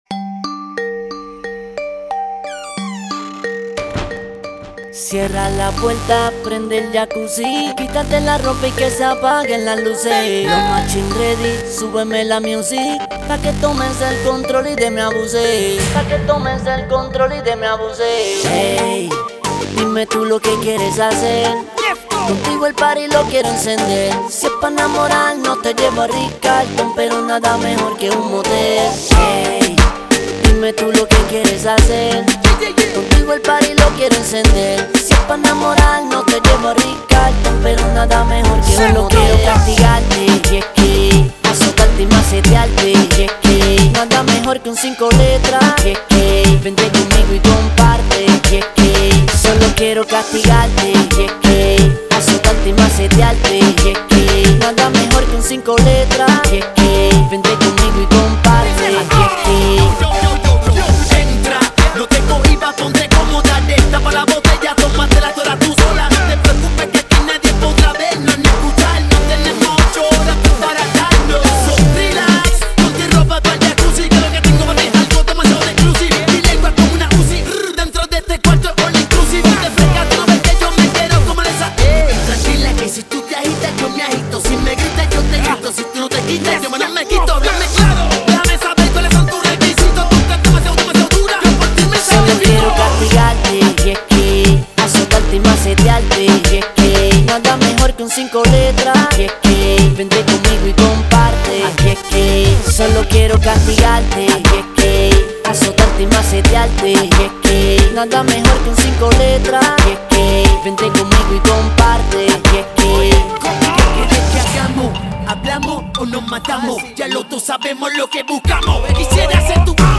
reggaeton music